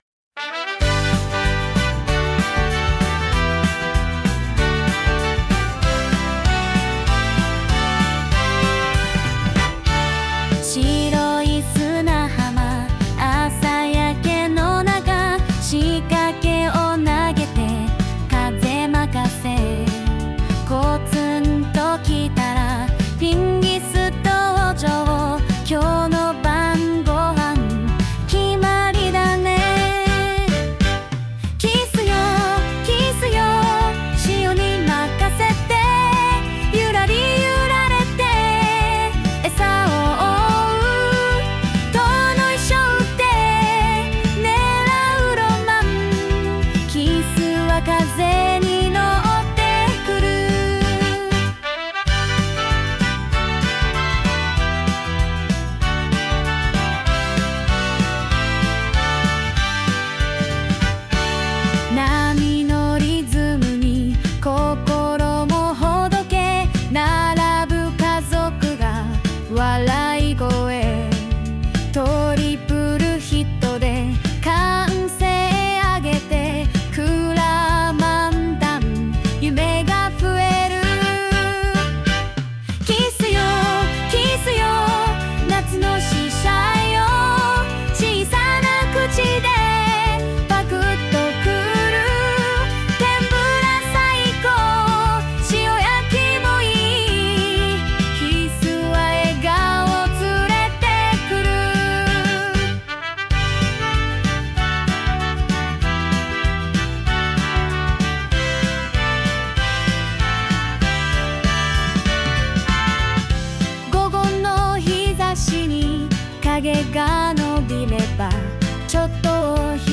作詞、作曲：釣太郎 with Suno AI , ChatGPT